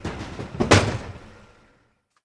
ball_crash.mp3